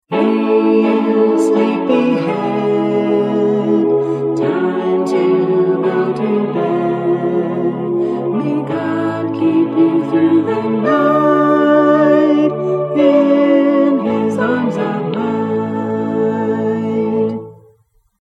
And this is a audio vocal version:
Lullaby-Hey-You-Sleepy-Head-(vocal).mp3